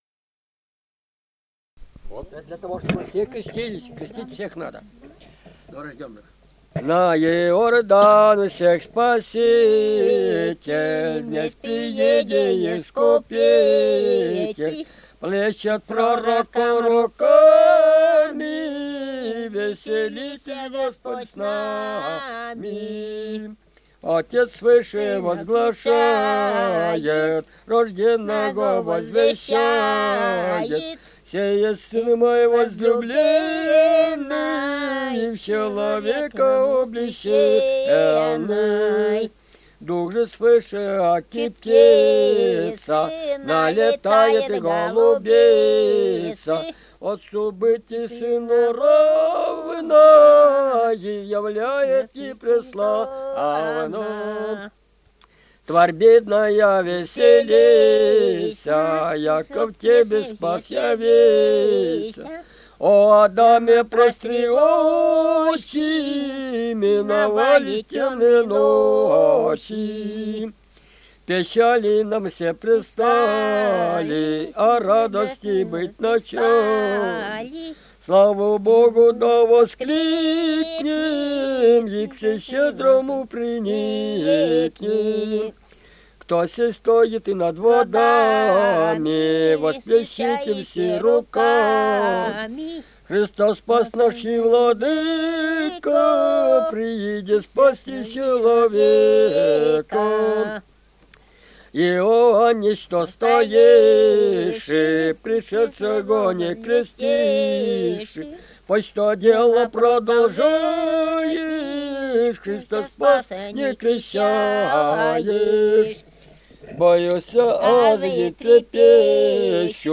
Русские песни Алтайского Беловодья 2 «На Иордан всех Спаситель», духовный стих к празднику Крещения Господня 6/19 января.
Республика Алтай, Усть-Коксинский район, с. Тихонькая, июнь 1980.